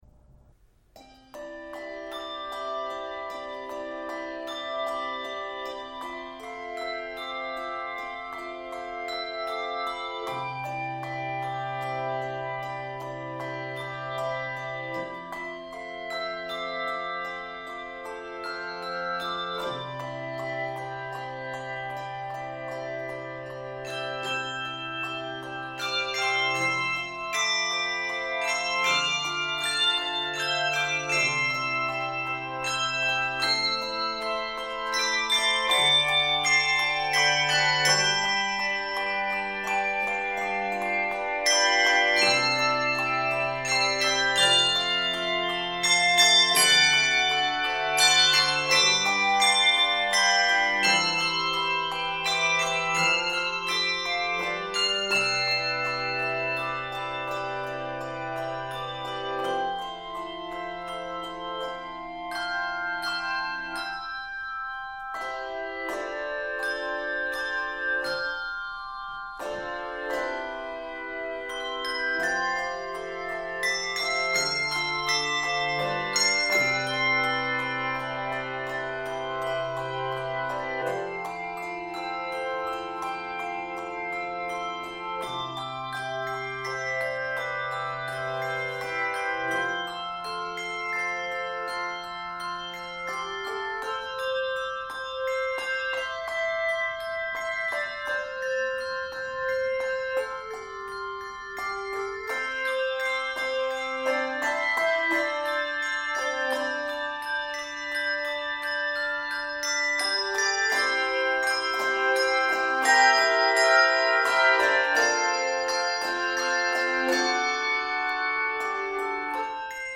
Meditative and reflective
flowing and expressive arrangement
Keys of C Major, G Major, and Eb Major.